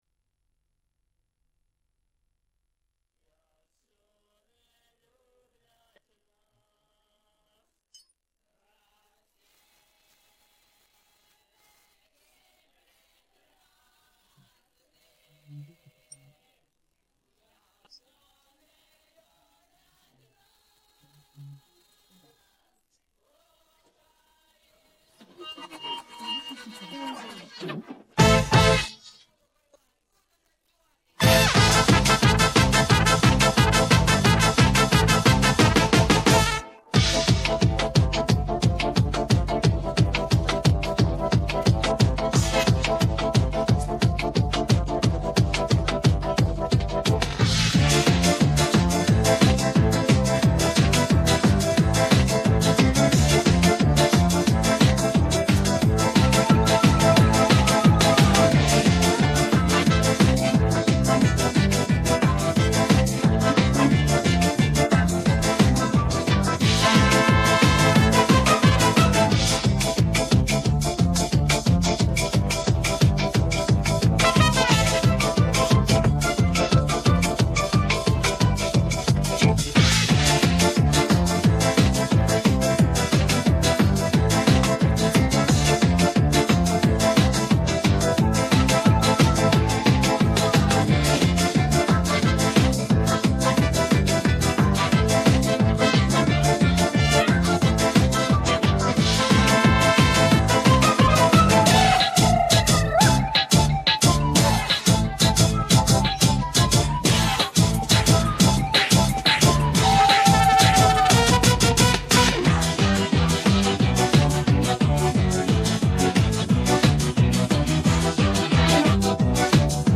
dance-pop караоке 16
Українські хіти караоке